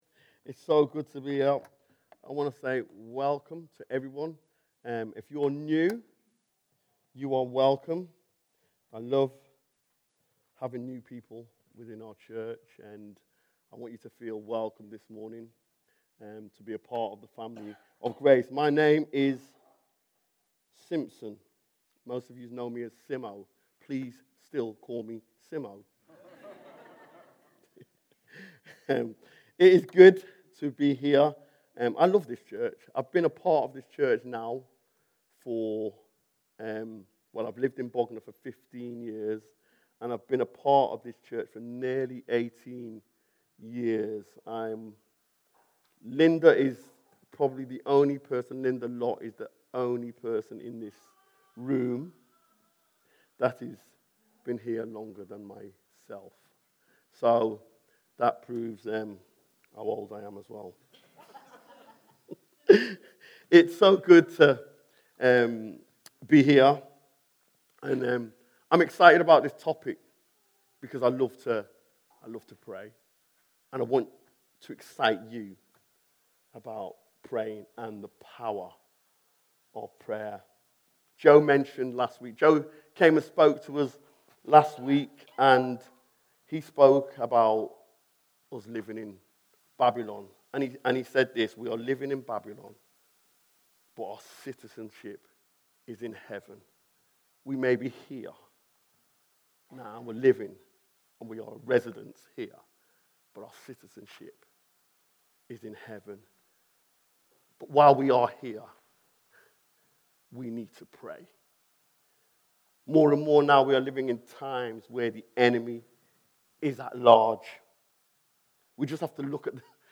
Series: Other Sermons 2025